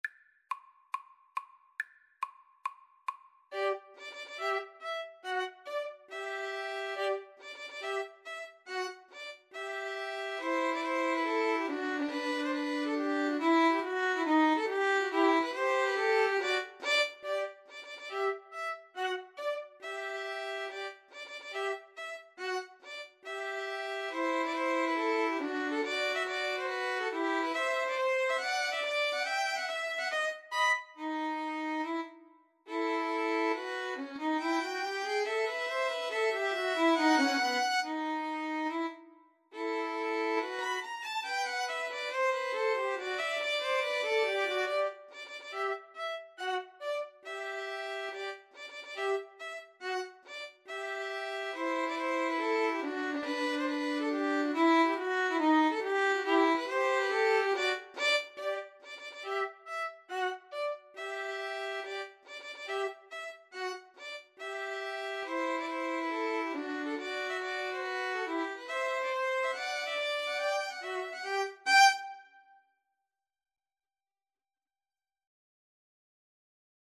G major (Sounding Pitch) (View more G major Music for Violin Trio )
Tempo di marcia =140
Classical (View more Classical Violin Trio Music)